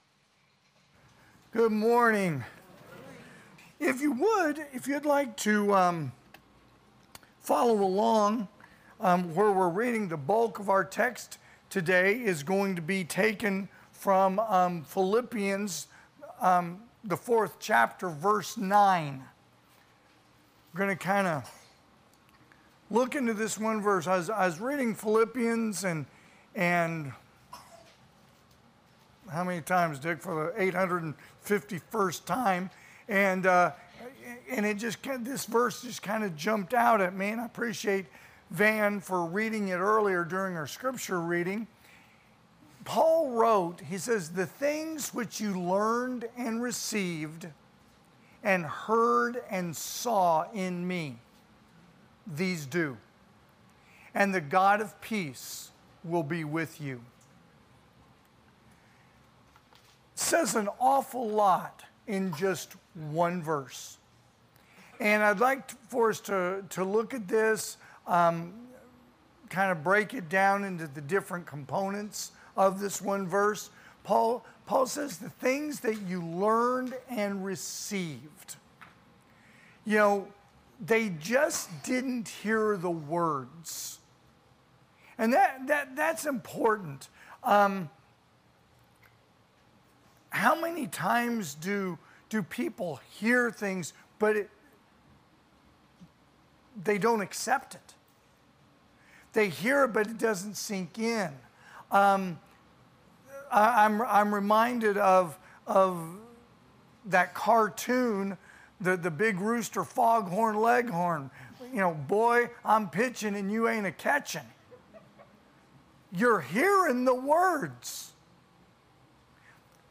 May 19,2024 (AM Worship) "Philippians 4
Sermons